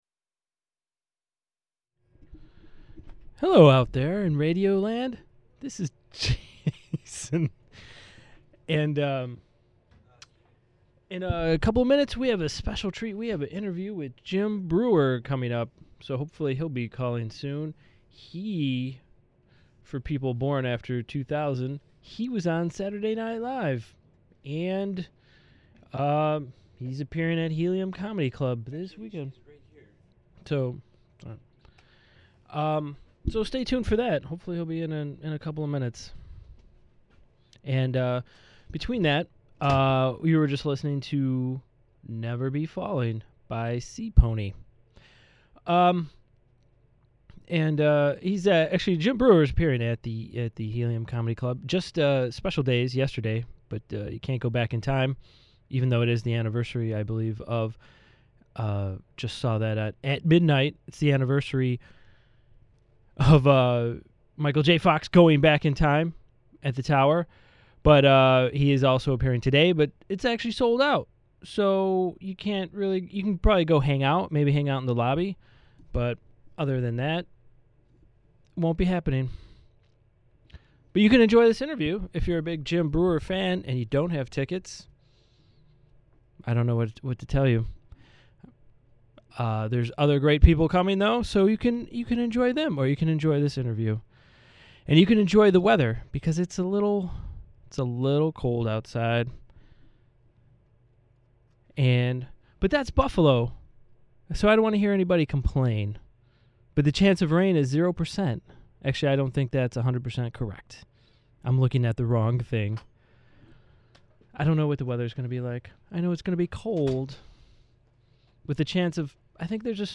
An in-depth interview with comedian Jim Breuer